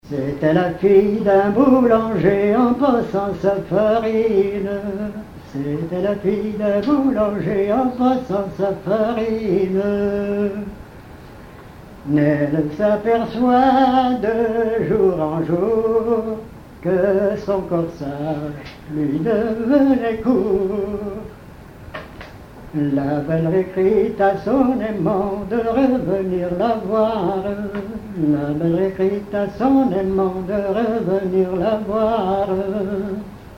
Genre strophique
Chansons traditionnelles
Pièce musicale inédite